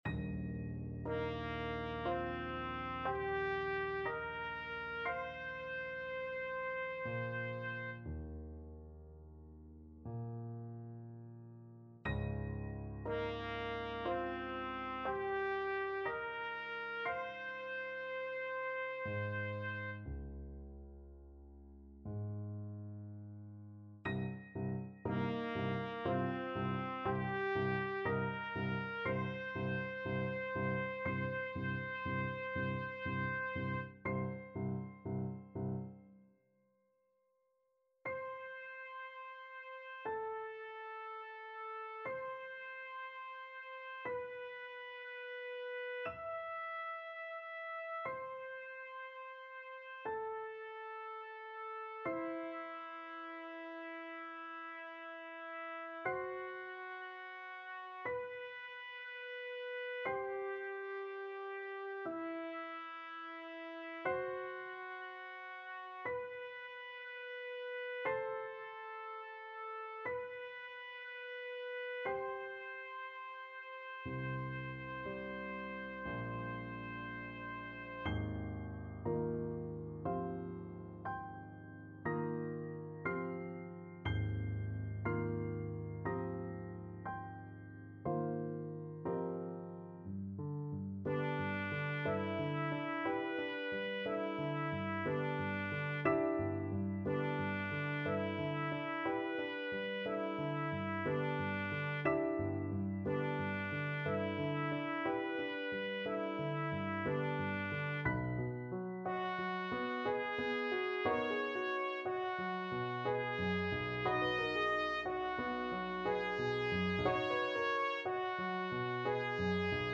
Trumpet version
6/4 (View more 6/4 Music)
Classical (View more Classical Trumpet Music)